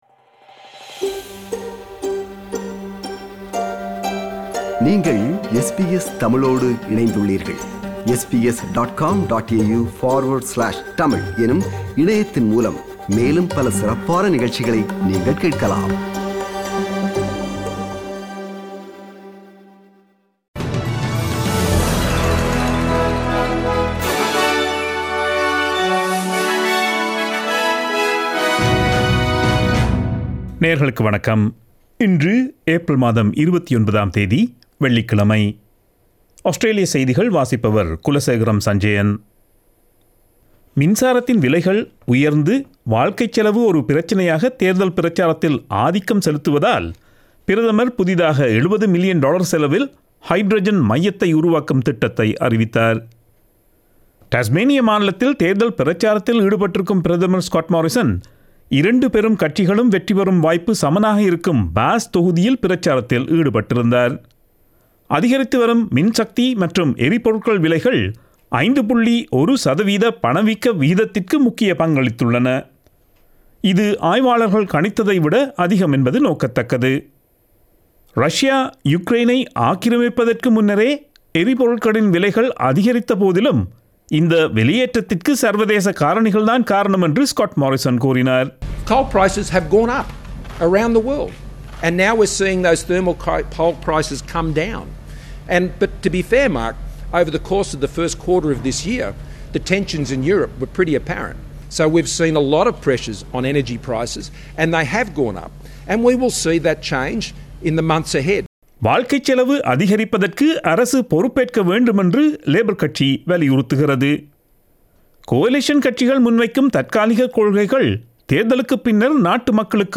Australian news bulletin for Friday 29 April 2022.